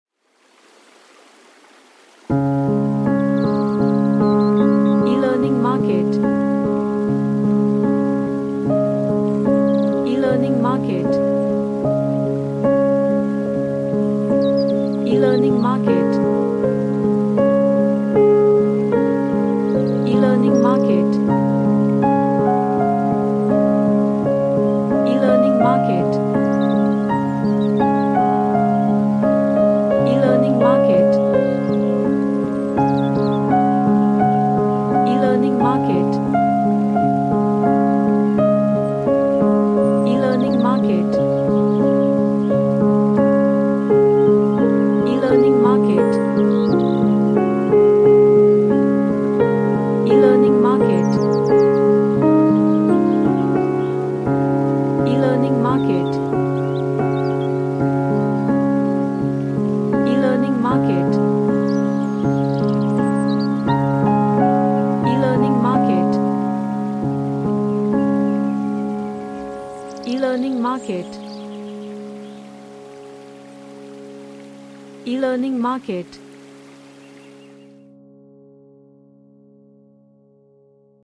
A emotional or meditative music
Happy / CheerfulRelaxation / Meditation